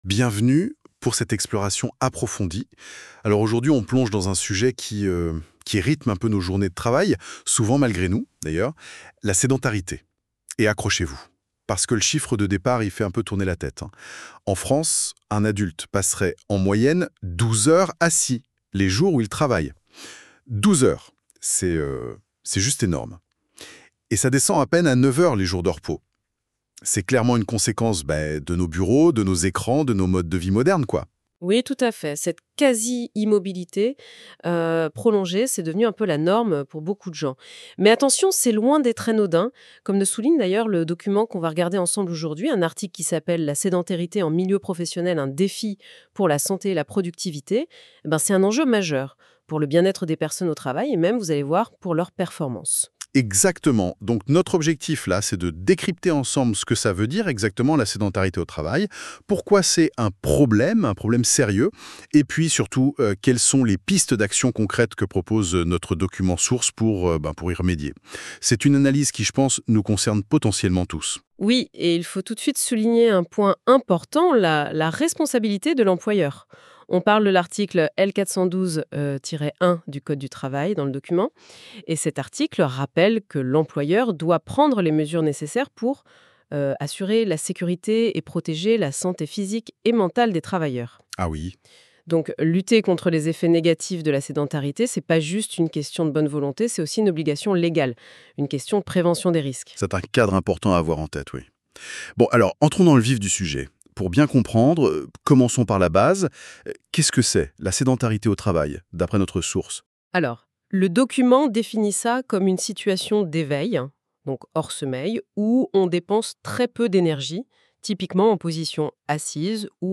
CEDIP - version générée à l'aide de l'IA NotebookLM